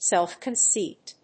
アクセントsélf‐concéit